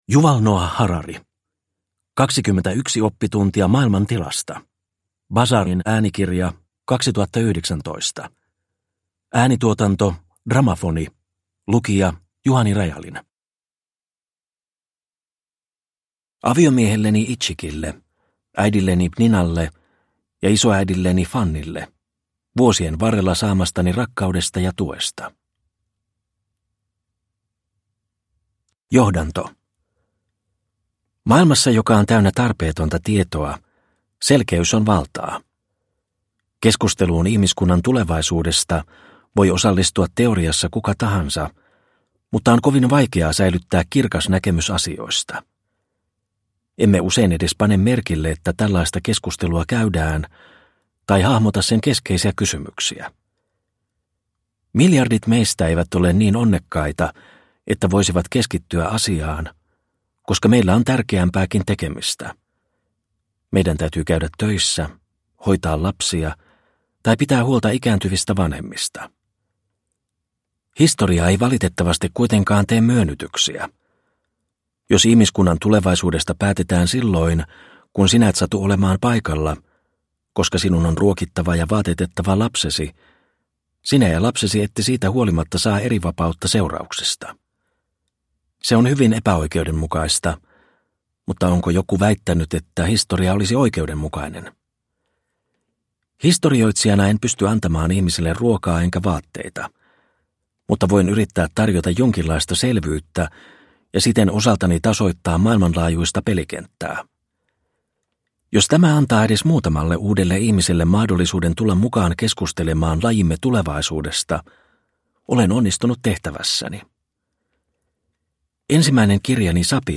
21 oppituntia maailman tilasta – Ljudbok – Laddas ner